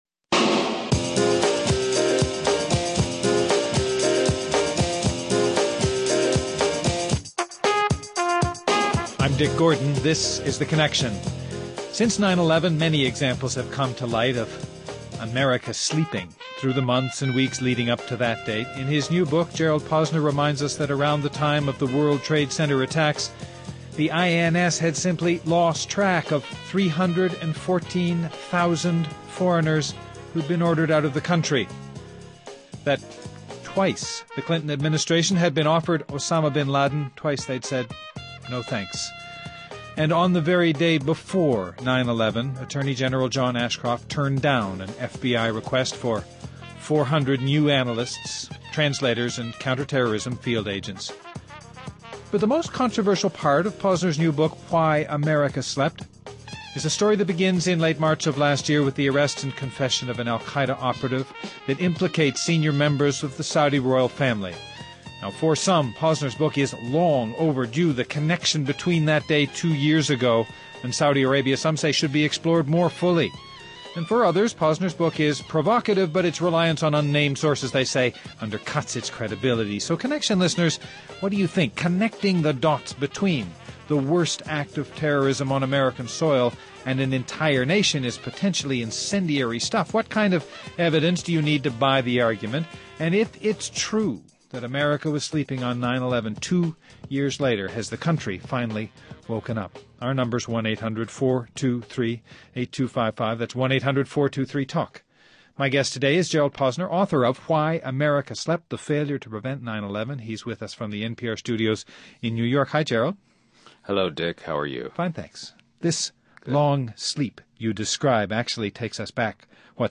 Approaching the second anniversary of September 11th, a conversation about what the Saudis might have known, and what American intelligence still won’t reveal.